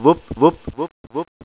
wupp-wupp-wupp" soll eine Drehtür sein...)
wupp.wav